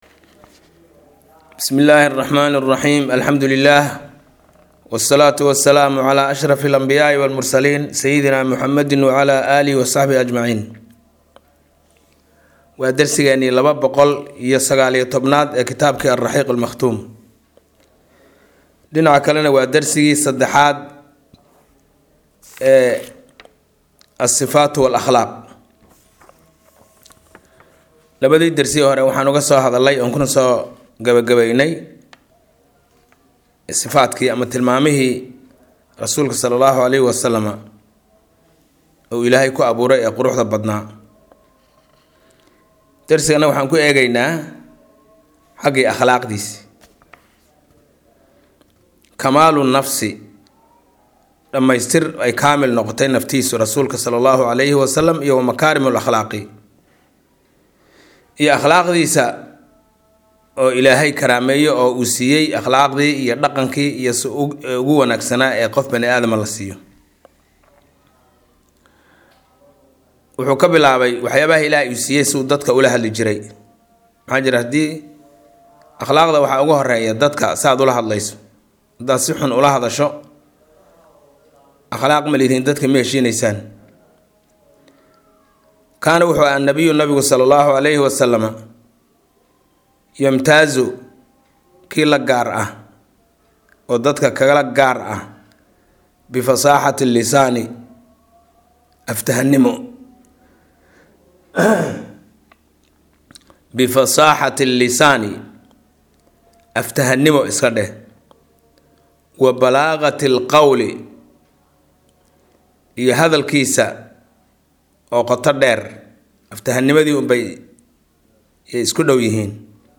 Maqal– Raxiiqul Makhtuum – Casharka 219aad